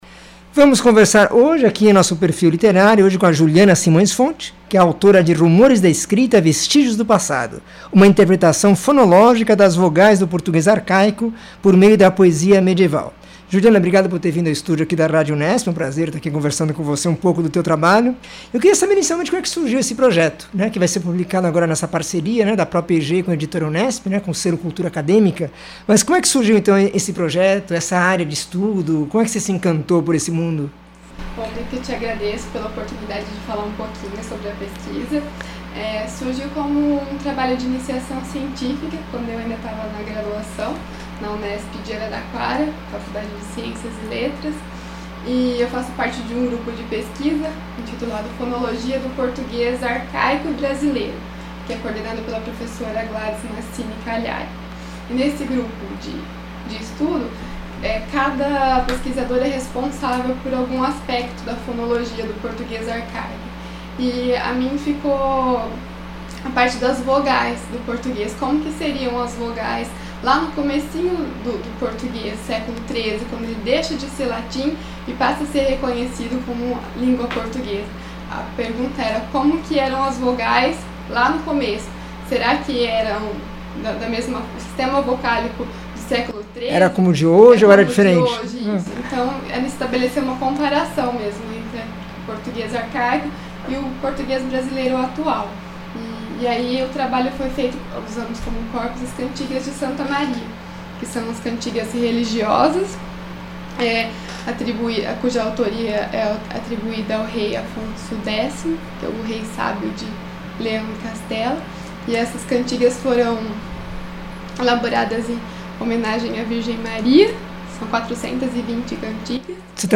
entrevista 1124